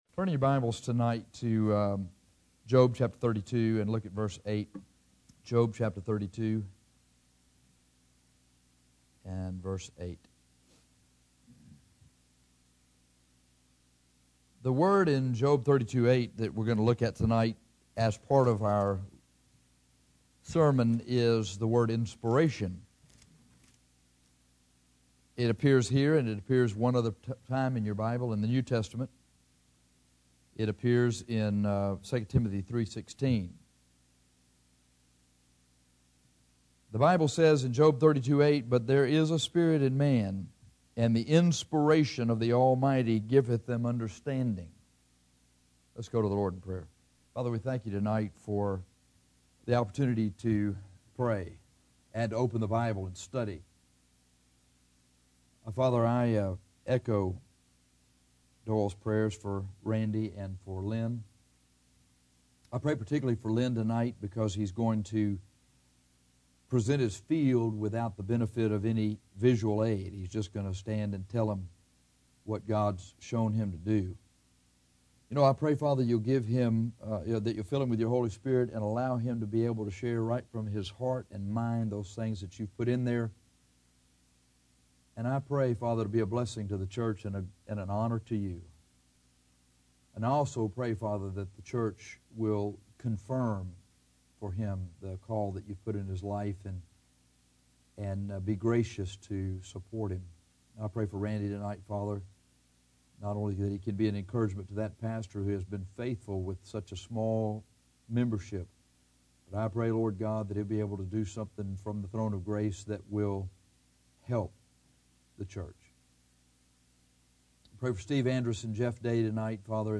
This sermon is on understanding the Bible.